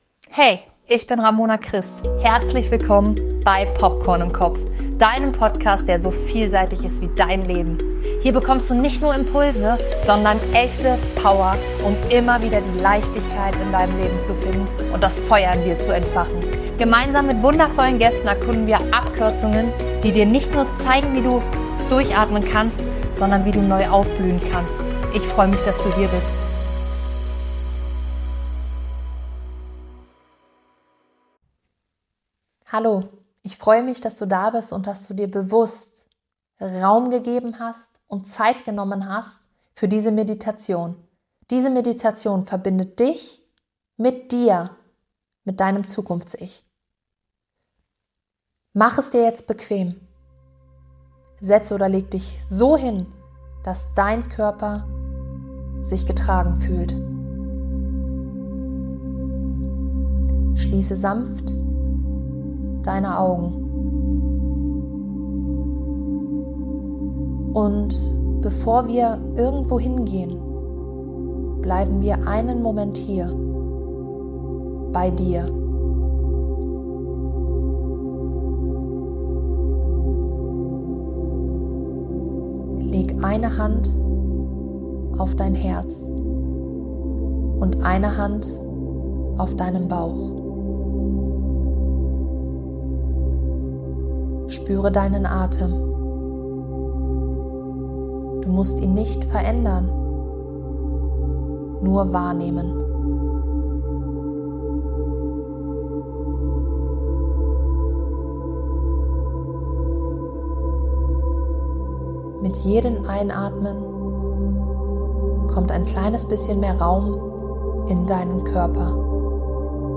In dieser geführten Meditation verbindest du dich bewusst mit deinem Zukunfts-Ich – nicht als Ziel, nicht als Ideal, sondern als inn...